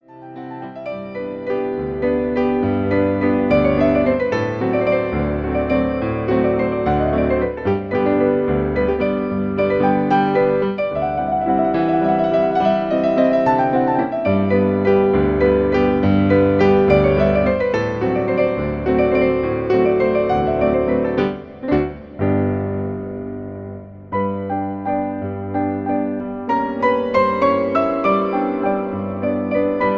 Jolly little waltz